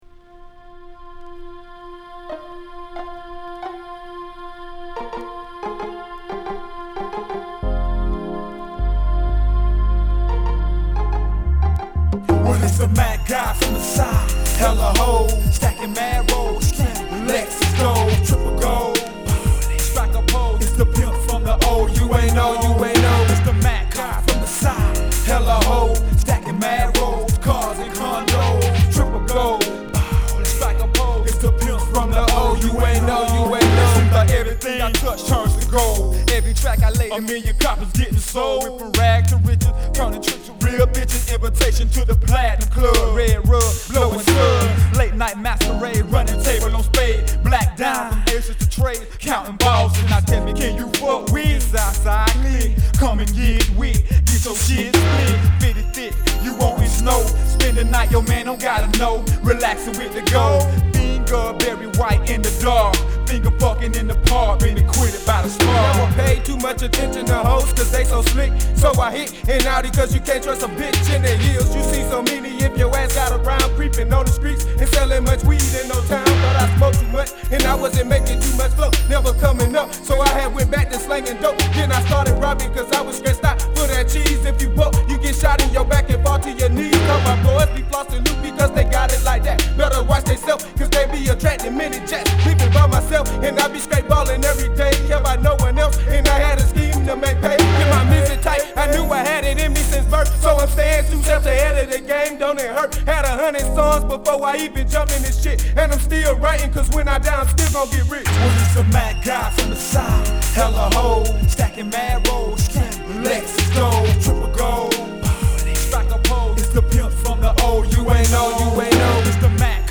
ホーム HIP HOP G-RAP 12' & LP C